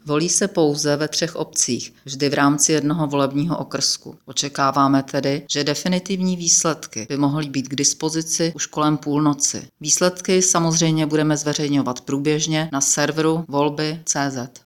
Vyjádření předsedkyně ČSÚ Ivy Ritschelové , soubor ve formátu MP3, 574.53 kB